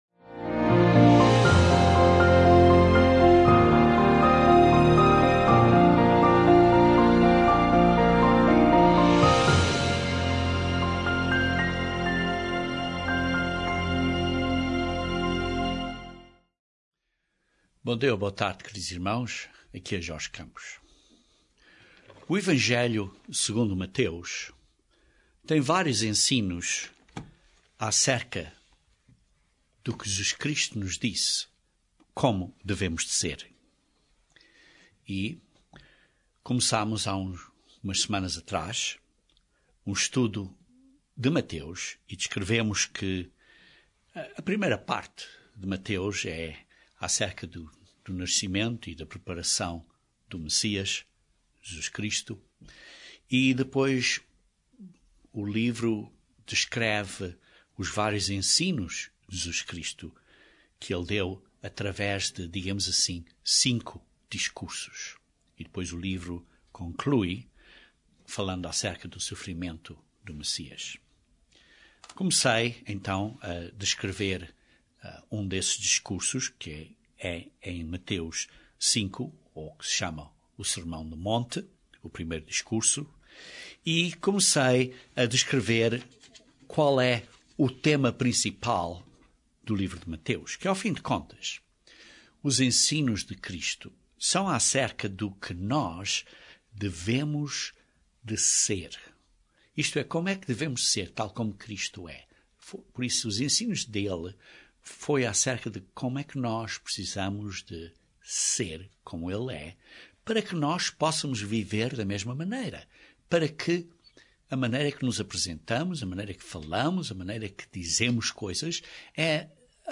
Estudo Bíblico de Mateus 5